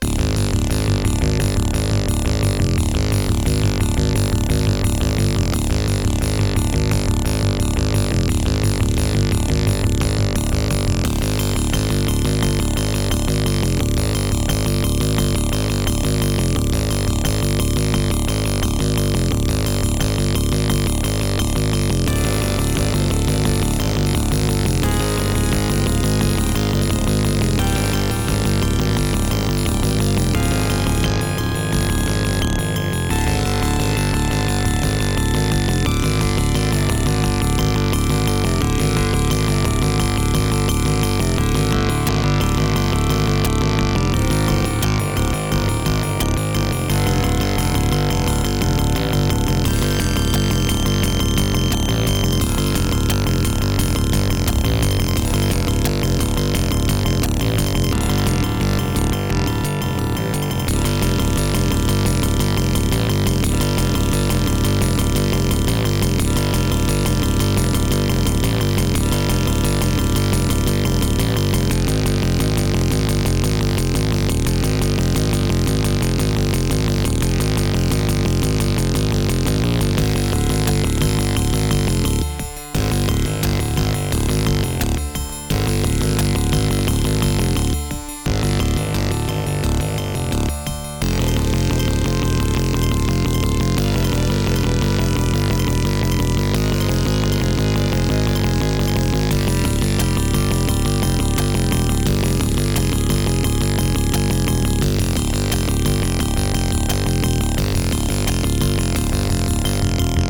Music: GM midi
Creative SoundBlaster 16 ct2740